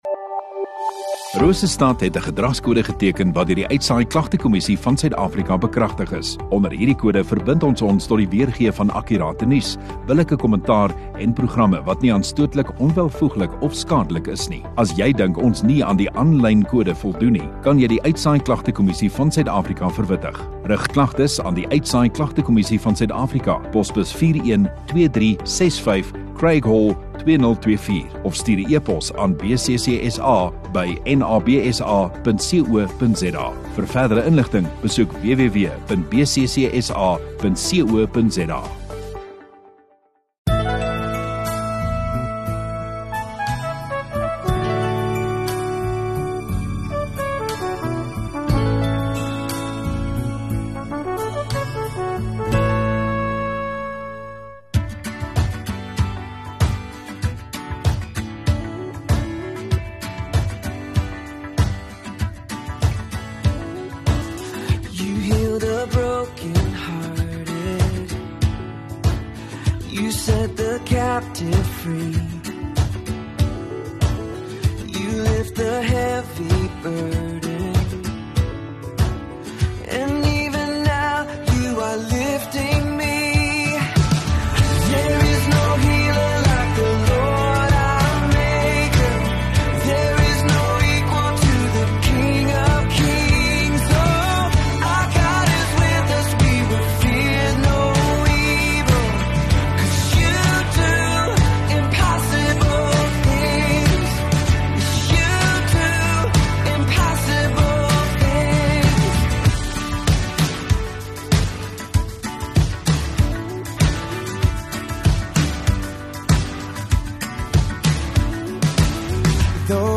28 Oct Saterdag Oggenddiens